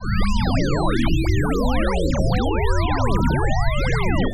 Shepard tone issues
You are correct, its not a ramp waveform at all.
shepardtonesoundswrong.aif